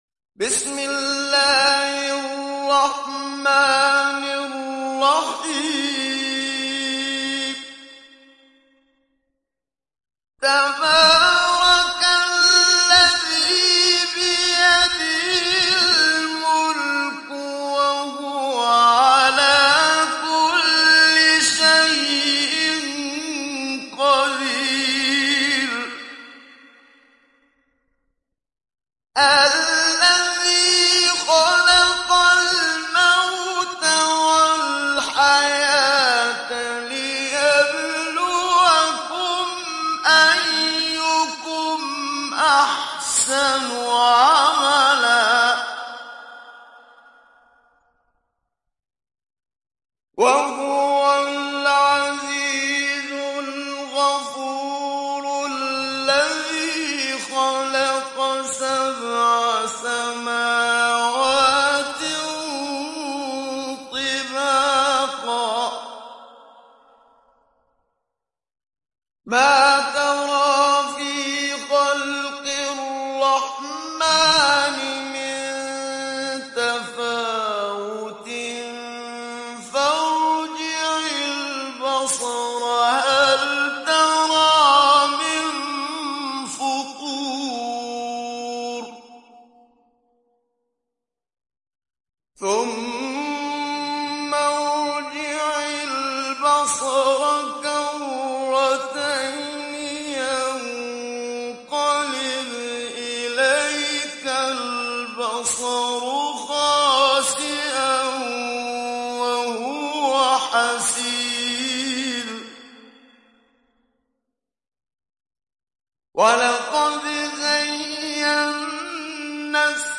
Sourate Al Mulk Télécharger mp3 Muhammad Siddiq Minshawi Mujawwad Riwayat Hafs an Assim, Téléchargez le Coran et écoutez les liens directs complets mp3
Télécharger Sourate Al Mulk Muhammad Siddiq Minshawi Mujawwad